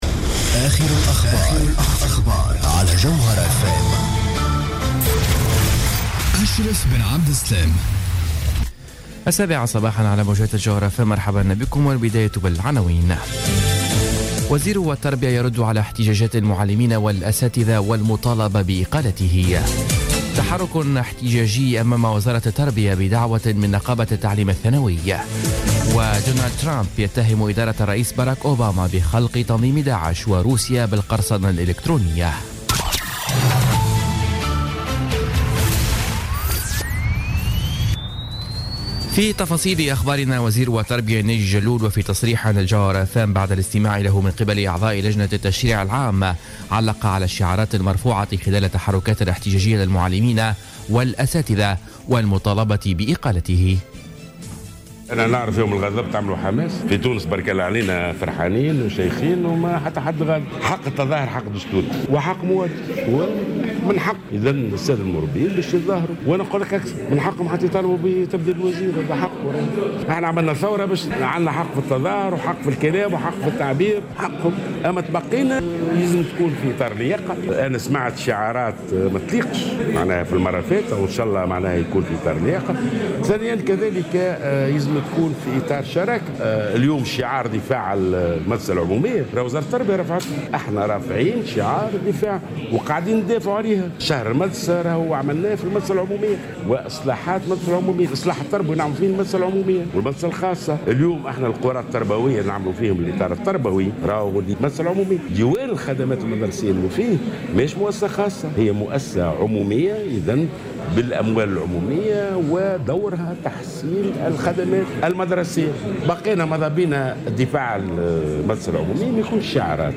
نشرة أخبار السابعة صباحا ليوم الخميس 12 جانفي 2017